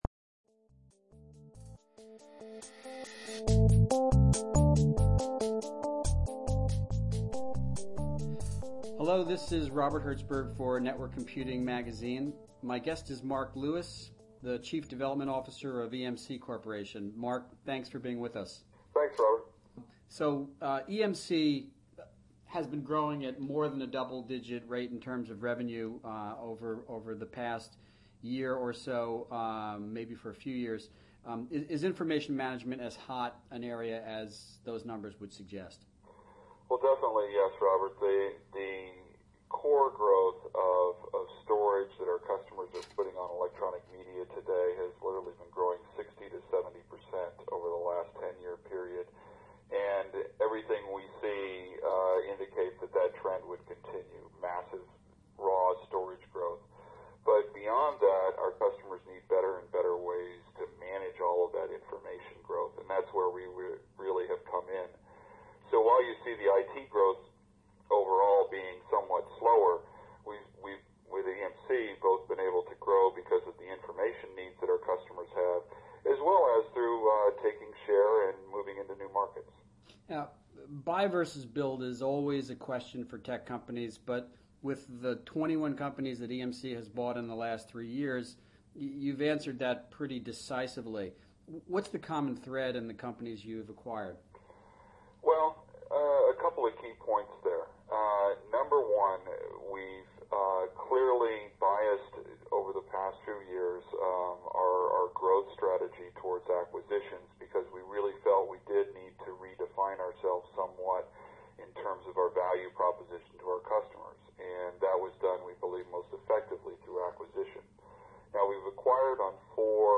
The NWC Interview